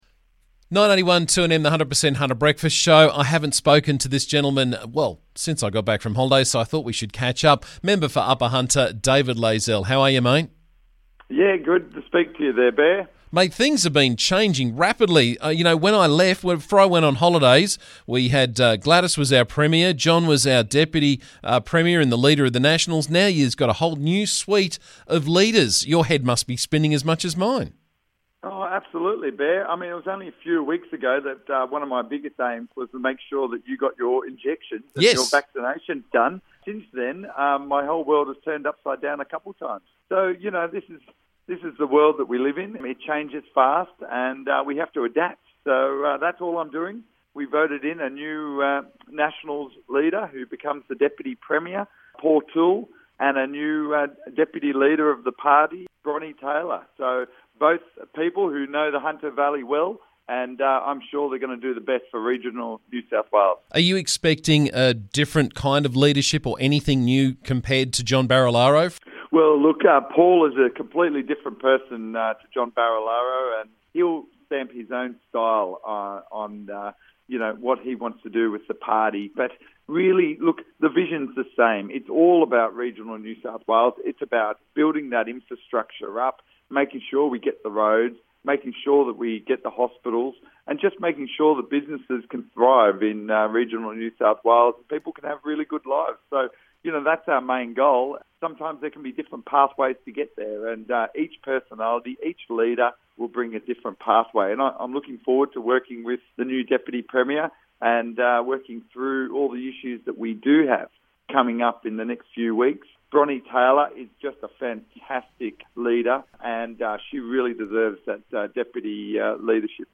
Member for Upper Hunter David Layzell joined me this morning to talk about the leadership changes in the NSW parliament this week.